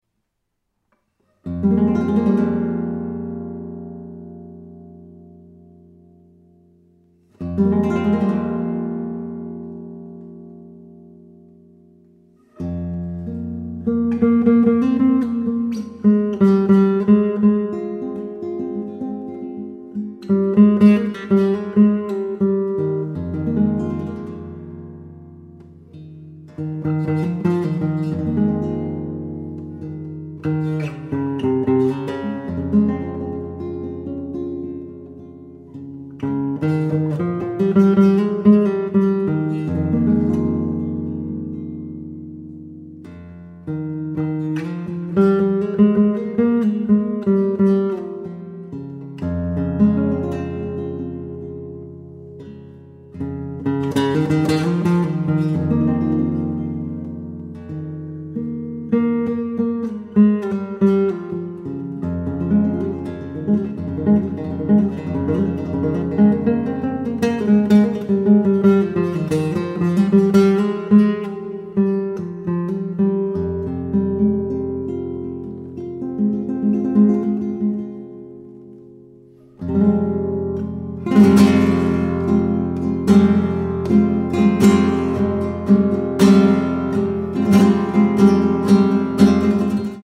composer, lute & oud player from Japan
Contemporary , Guitar etc.
, Relaxing / Meditative